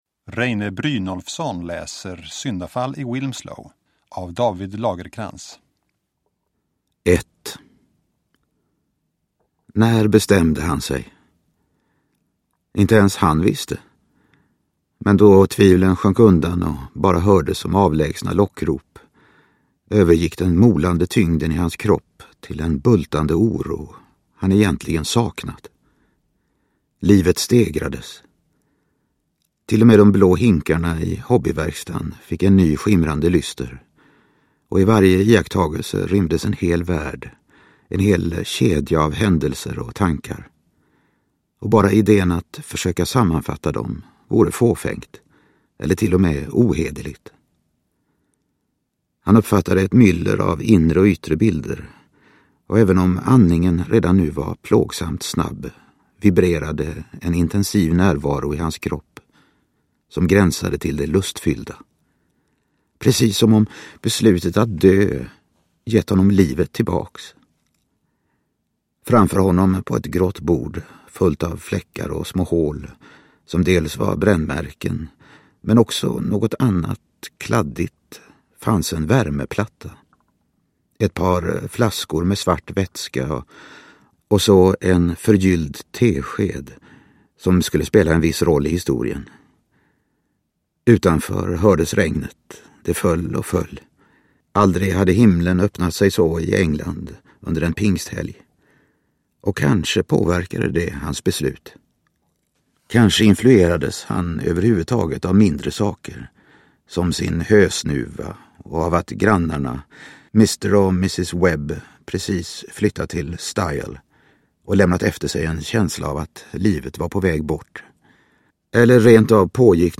Downloadable Audiobook